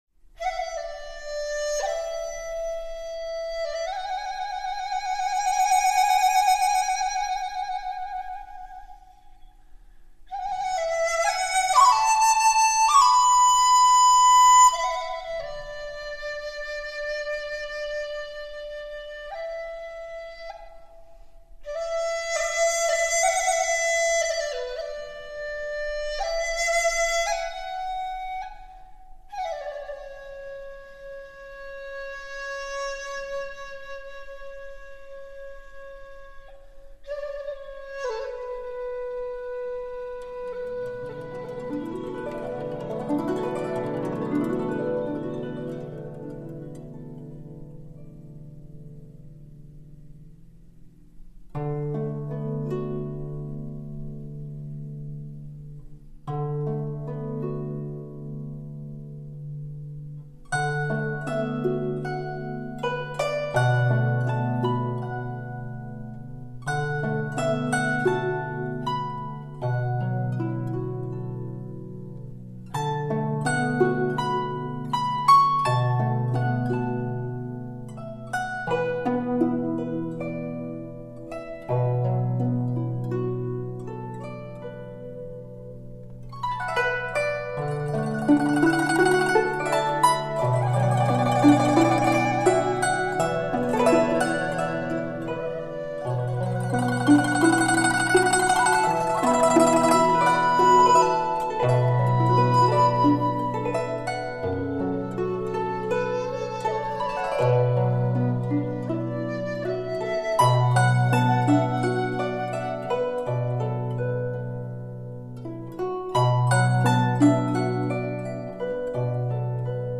[纯音]
HQCD的成品达到了接近原始音乐母带的完美音质。
唯美和现代随想完美融合，呈现出令人惊艳的丰富质感和怀古幽情。
★千年古筝名琴，音质细腻扣人心弦，演绎出最撩动人心却又有一种隔纱看花的间离美感。